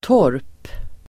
Ladda ner uttalet
torp substantiv (även om sommarhus på landet), croft [also used of a summer cottage in the country]Uttal: [tår:p] Böjningar: torpet, torp, torpenDefinition: (bostadshus till ett) litet jordbruk
torp.mp3